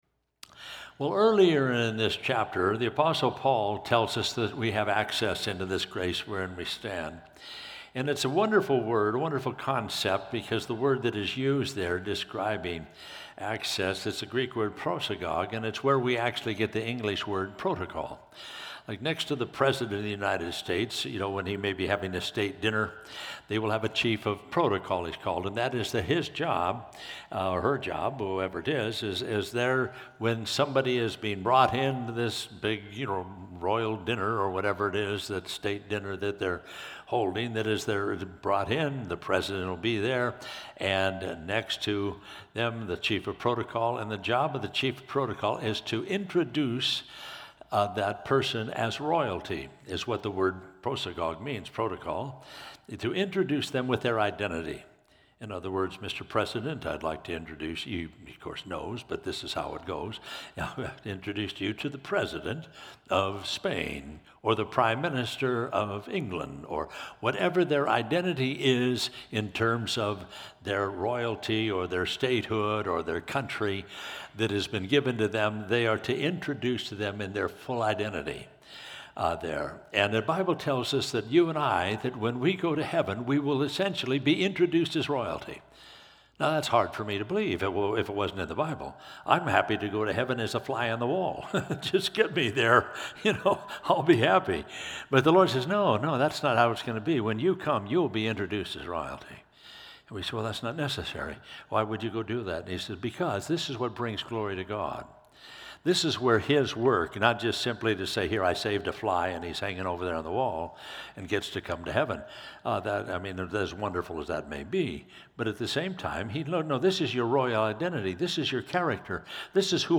Calvary Chapel Rialto – Sermons and Notes